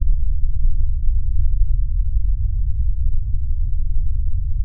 Slow motion sound
alien film gta horror maxpayne motion scary slow sound effect free sound royalty free Movies & TV